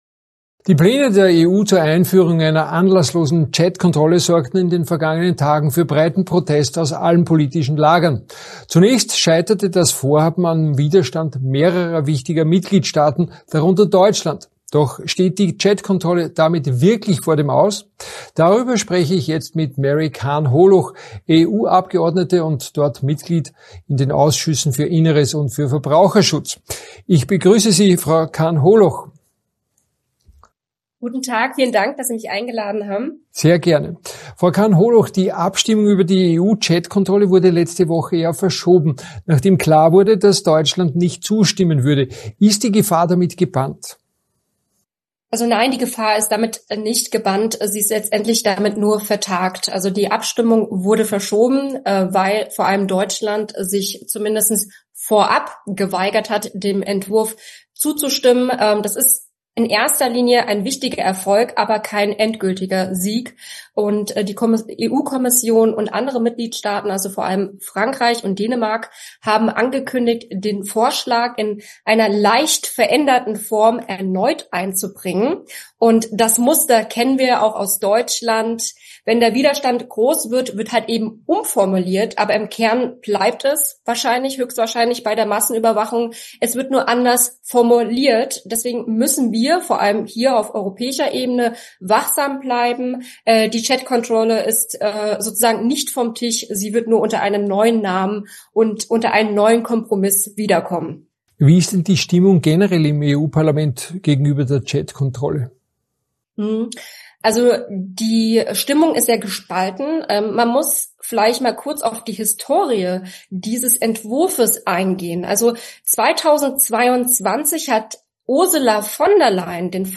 AUF1-Interview warnt sie vor einer Neuauflage der Chatkontrolle und